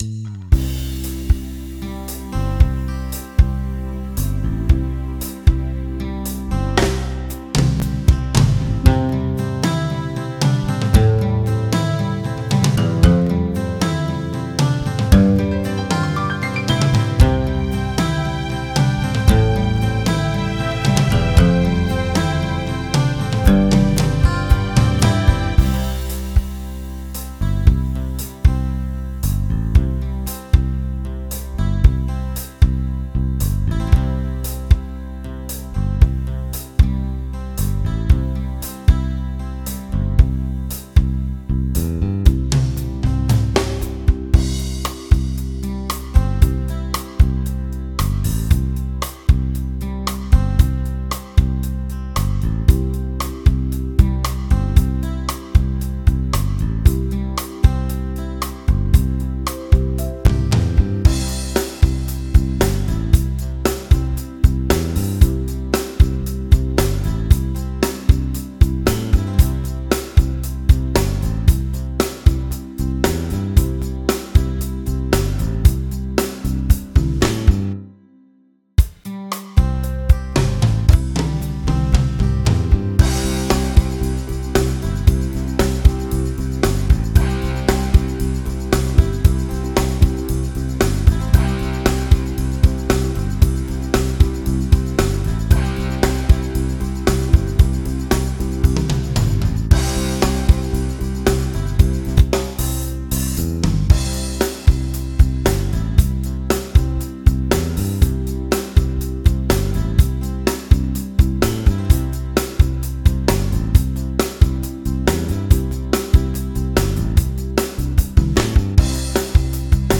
I start to add audiodrums...
8beat1drums.mp3